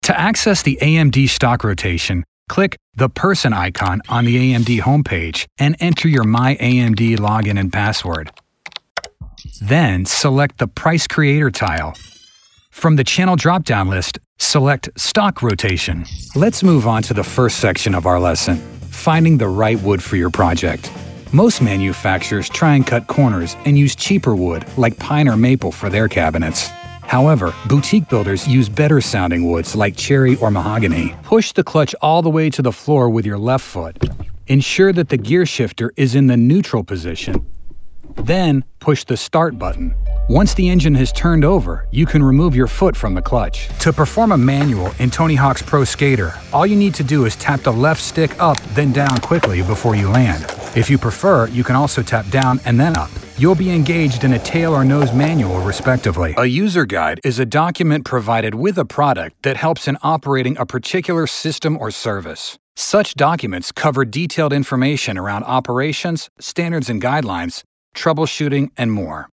Bright, Upbeat, Youthful.
eLearning